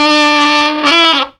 GROWL.wav